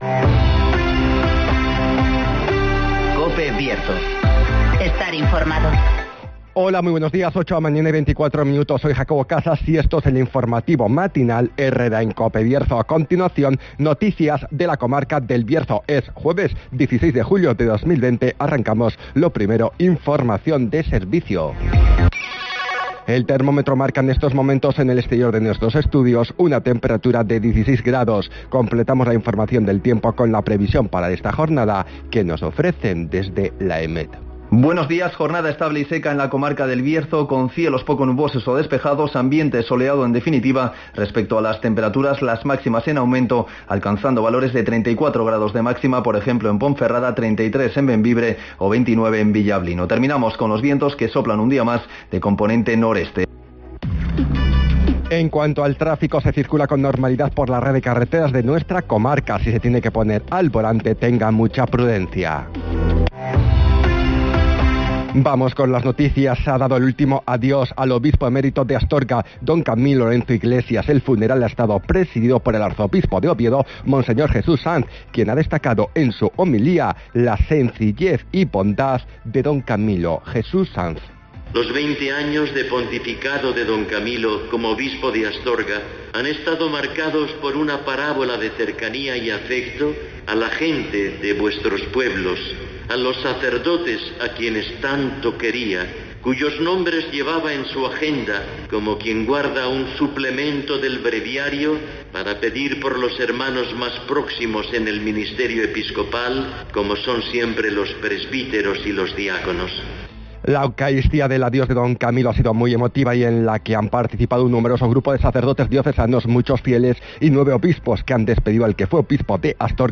INFORMATIVOS
Conocemos las noticias de las últimas horas de nuestra comarca, con las voces de los protagonistas
-Palabras de Jesús Sanz, arzobispo de Oviedo